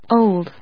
/óʊld(米国英語), ˈəʊld(英国英語)/